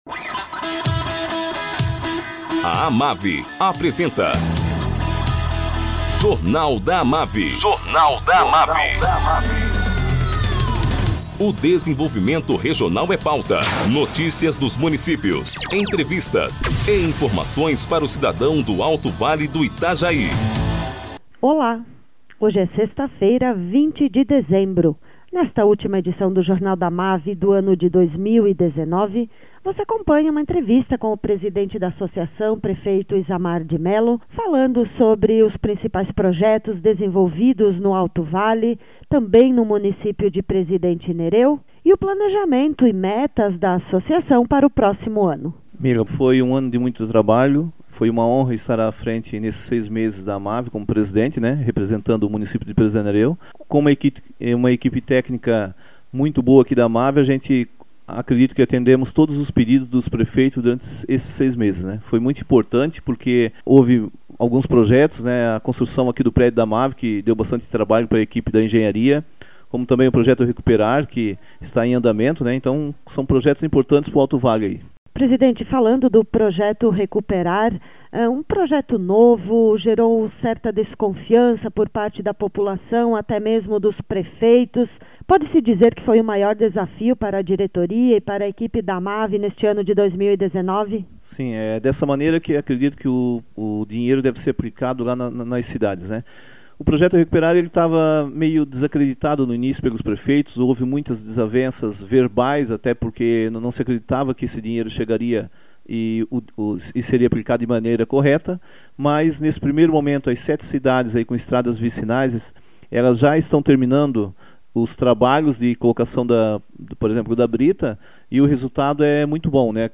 Presidente da AMAVI, prefeito Isamar de Melo, faz uma valiação do ano de 2019.